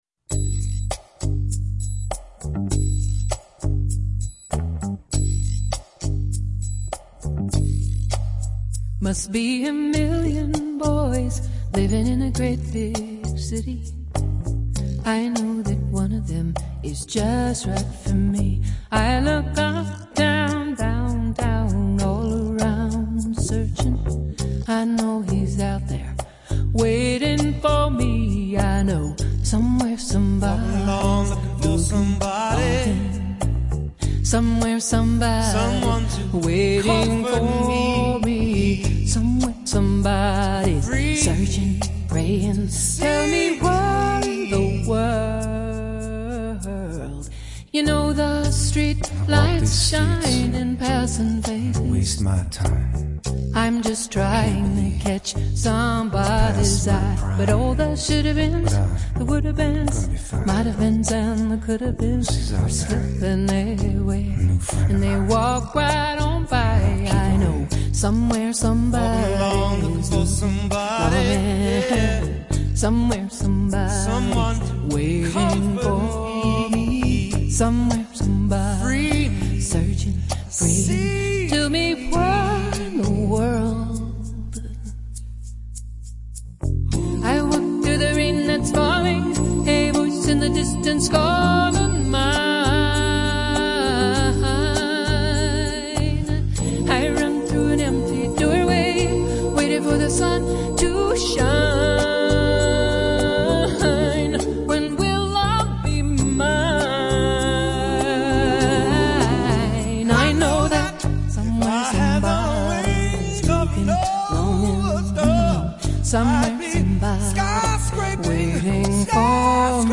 录制于１９９２年的这张专辑
人声温暖、通透、定位好、音场宽